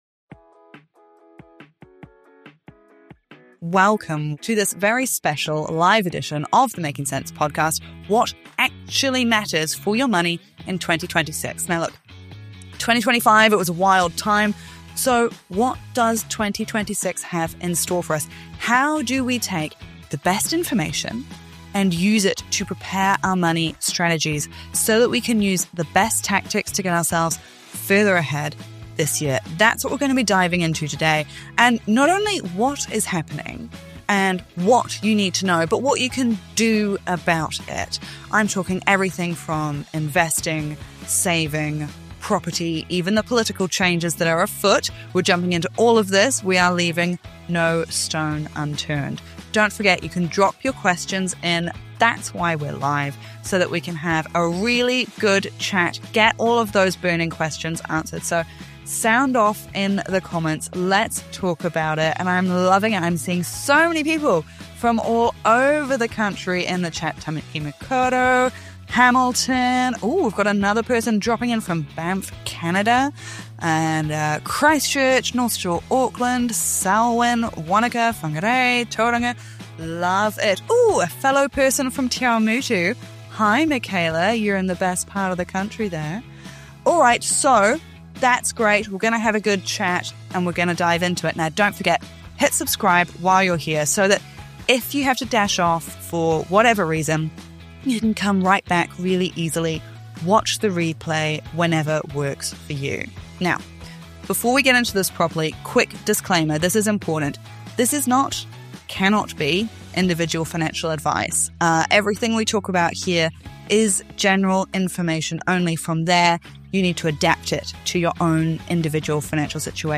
What actually matters for your money in 2026 - WEBINAR REPLAY